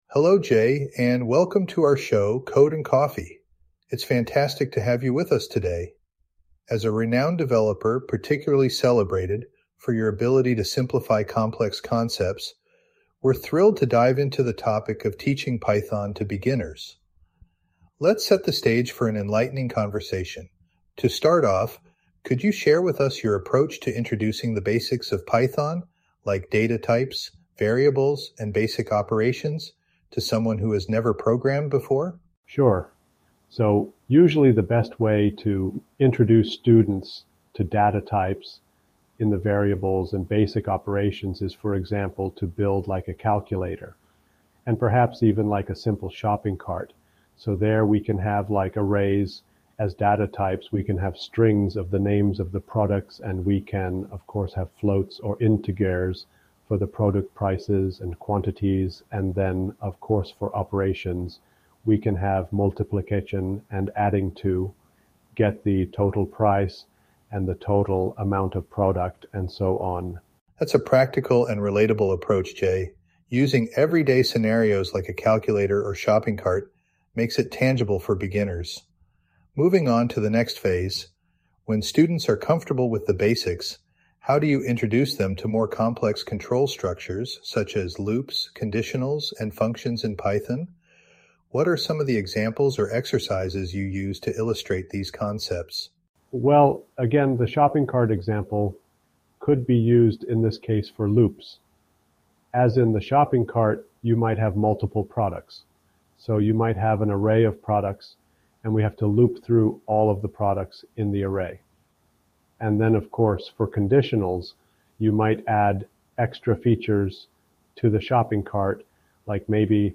Interview with a pythonista